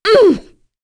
Naila-Vox_Damage_02.wav